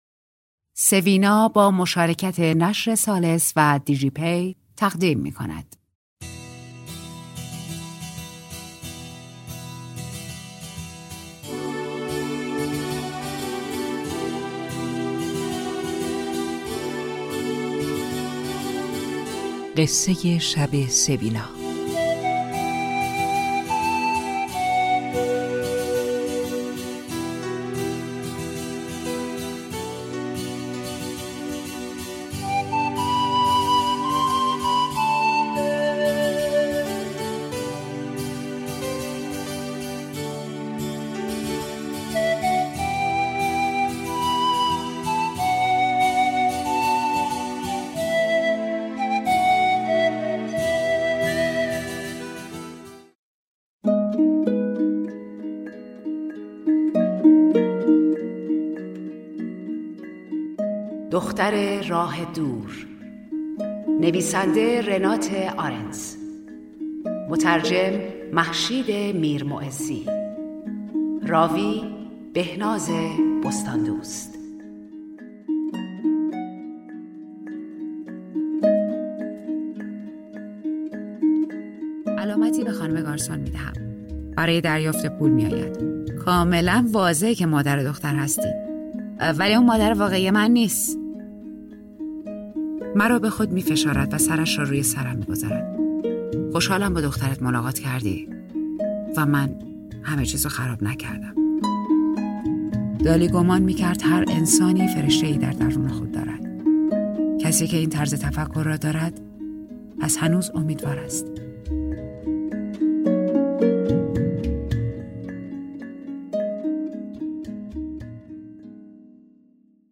خوانش رمان دختر راه دور